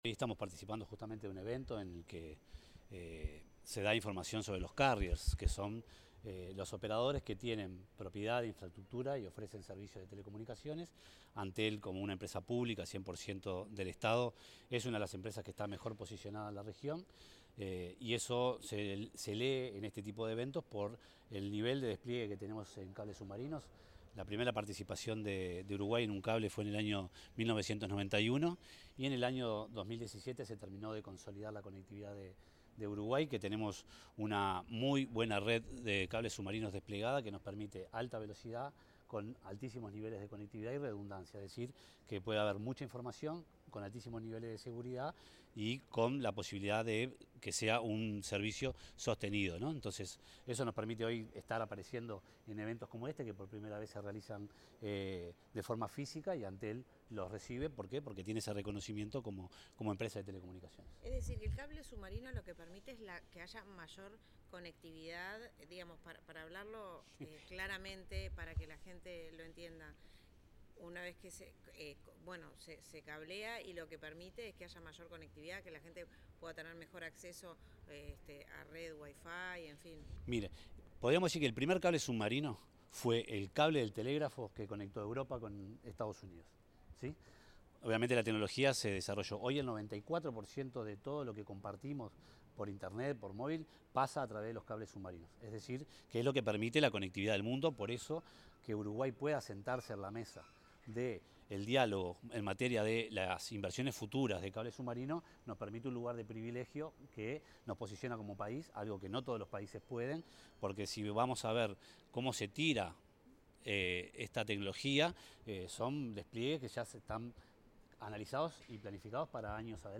Declaraciones del vicepresidente de Antel, Pablo Álvarez
Declaraciones del vicepresidente de Antel, Pablo Álvarez 29/10/2025 Compartir Facebook X Copiar enlace WhatsApp LinkedIn En el marco del Carriers Map Day edición 2025, evento dedicado a mostrar, analizar y proyectar el tendido de cables submarinos de fibra óptica, el vicepresidente de Antel, Pablo Álvarez, realizó declaraciones. Expresó que Uruguay dispone de una buena red de cables submarinos desplegada, que permite alta velocidad de conectividad.